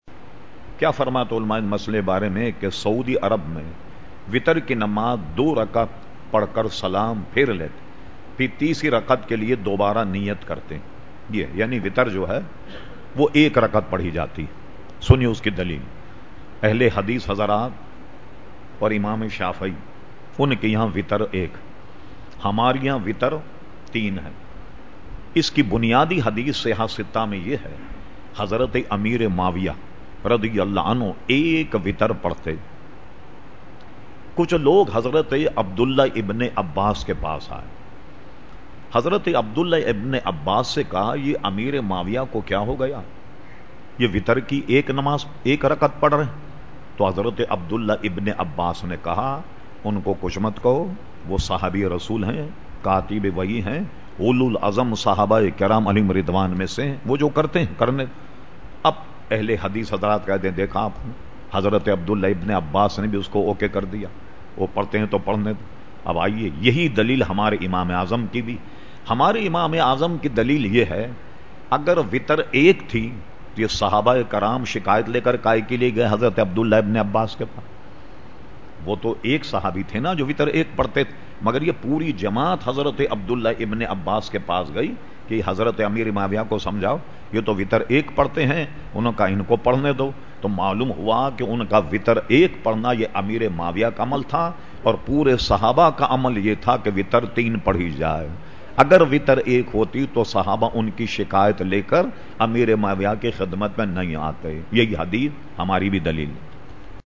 Q/A Program held on Sunday 26 September 2010 at Masjid Habib Karachi.